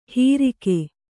♪ hīrike